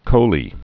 (chōlē)